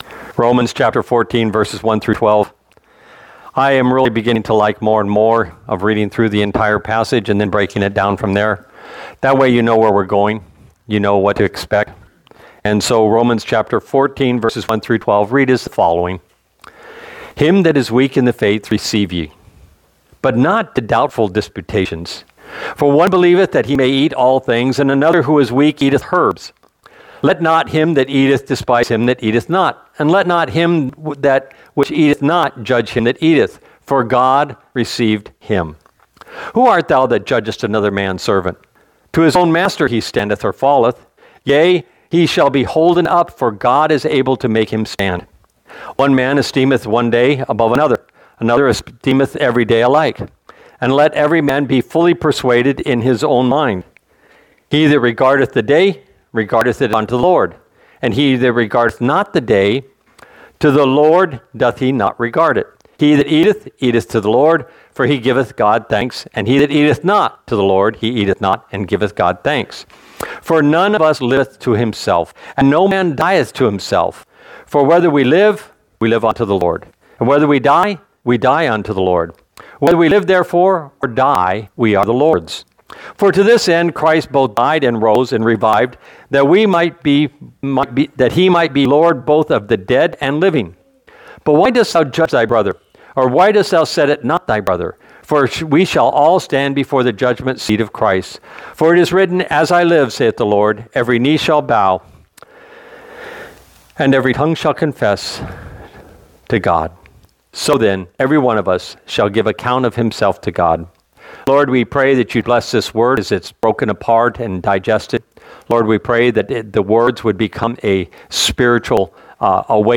All Sermons Receiving the Weak Romans 14:1-12 29 September 2025 Series